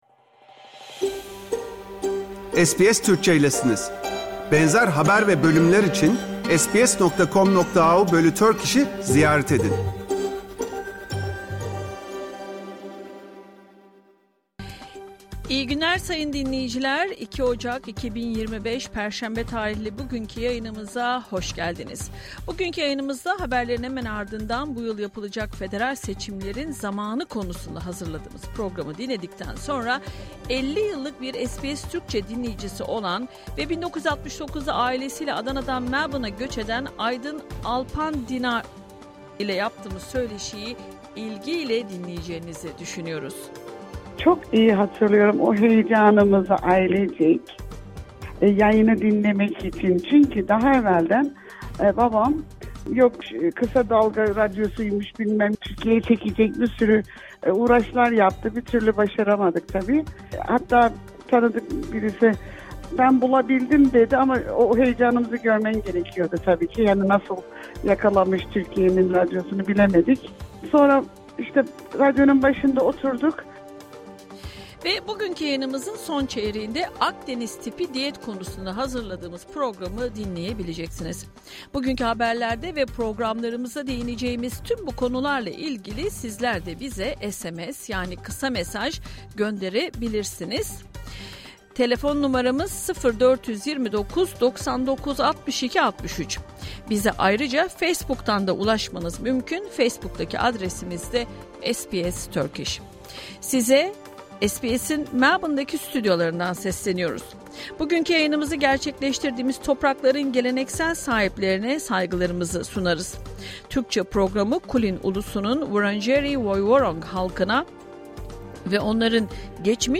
Hafta içi Salı hariç her gün Avustralya doğu kıyıları saati ile 14:00 ile 15:00 arasında yayınlanan SBS Türkçe radyo programını artık reklamsız, müziksiz ve kesintisiz bir şekilde dinleyebilirsiniz. 🎧
SBS Türkçe haber bülteni Bu yıl yapılacak federal seçimlerin ne zaman yapılacağına dair uzman görüşlerini sizler için derledik.